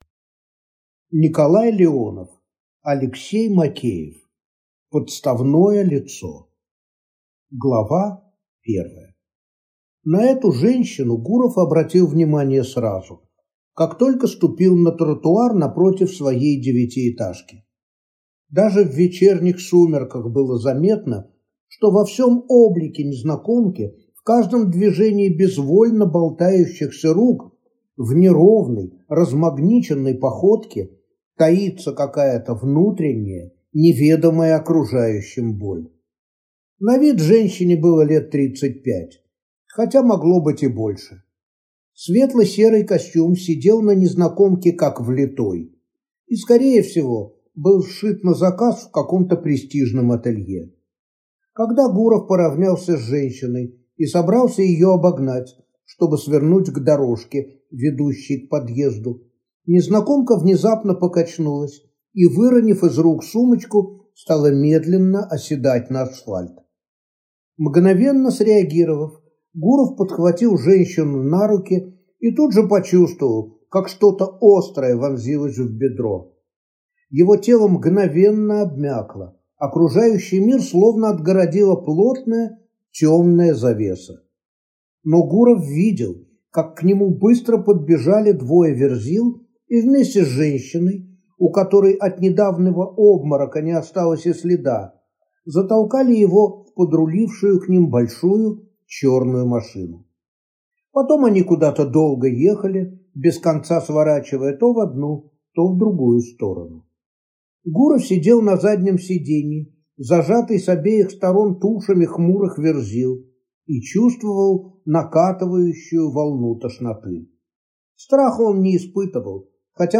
Аудиокнига Подставное лицо | Библиотека аудиокниг